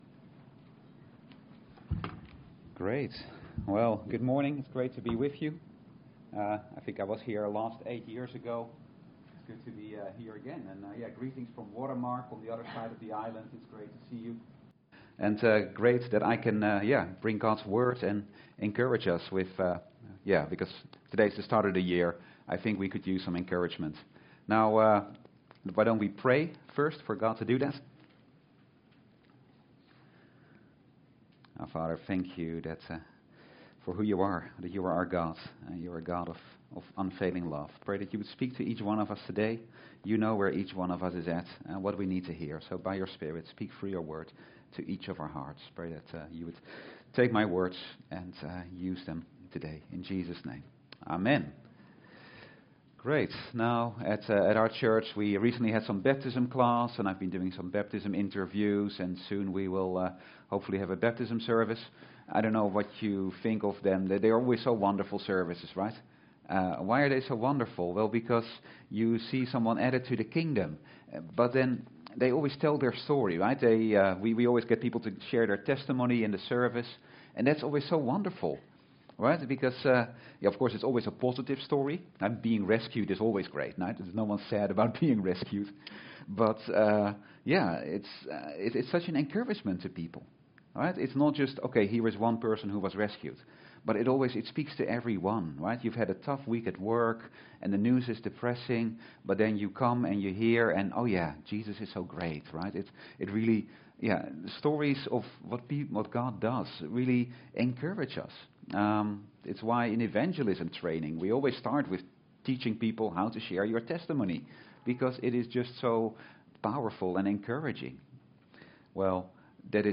Standalone Sermons